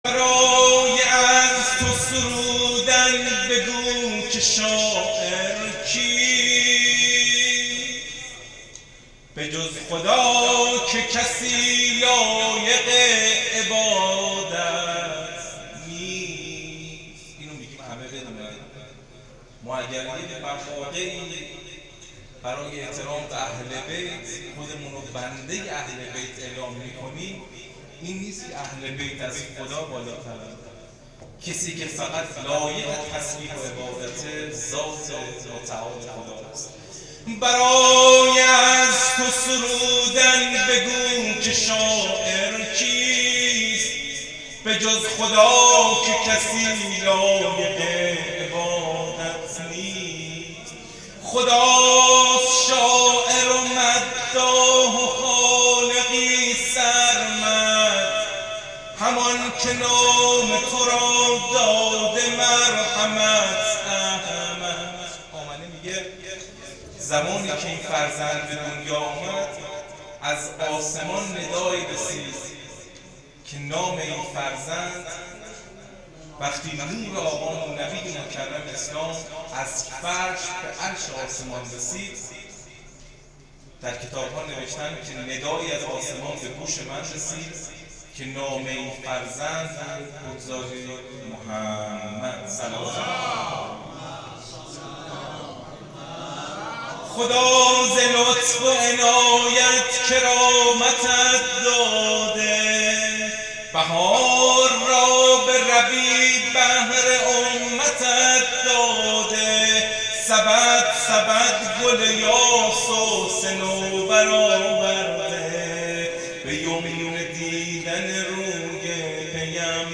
مدح برای از تو سرودن ، میلاد پیامبر اسلام ص